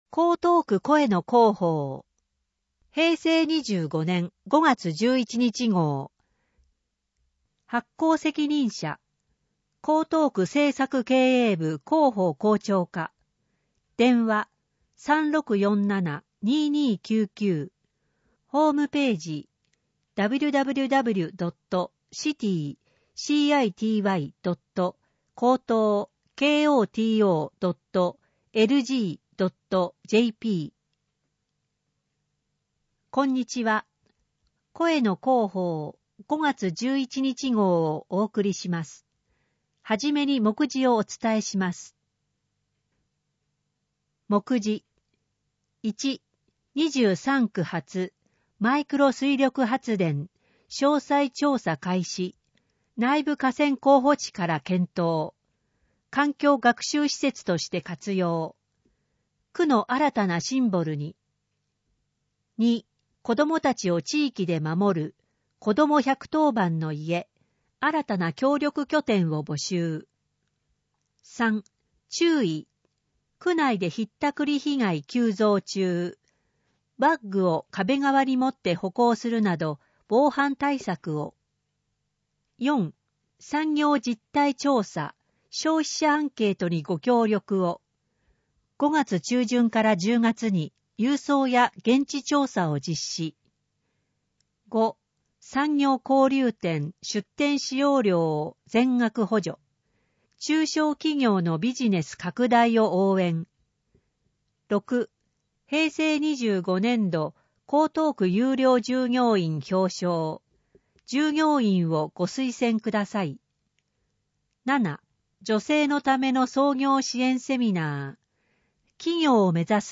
声の広報 平成25年5月11日号(1-10面)